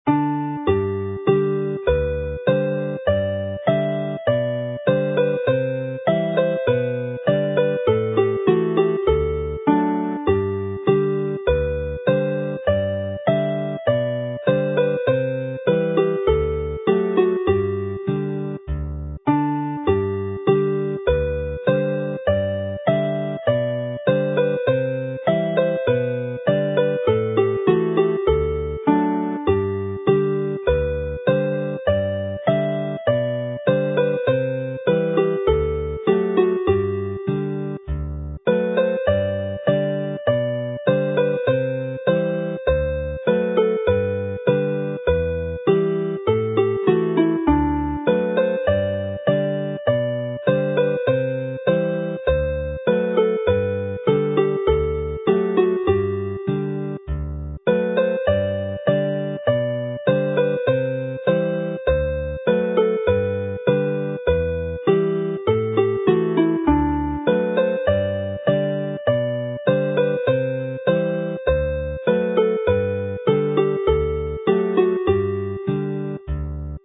is a jig that has been adapted to polka timing for this set,